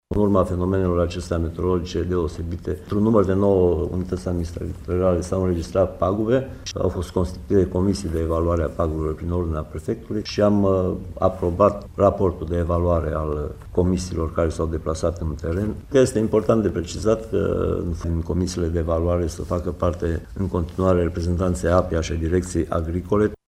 Valoarea estimativă a pagubelor se ridică la 665.000 de lei, a mai spus Mircea Dușa: